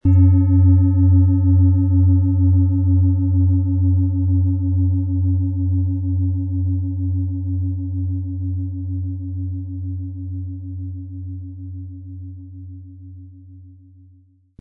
OM Ton
• Tiefster Ton: Jupiter
Im Sound-Player - Jetzt reinhören können Sie den Original-Ton genau dieser Schale anhören.
Mit einem sanften Anspiel "zaubern" Sie aus der OM-Ton mit dem beigelegten Klöppel harmonische Töne.
PlanetentöneOM Ton & Jupiter & OM-Ton (Höchster Ton)
MaterialBronze